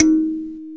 SANZA 2 D#3.WAV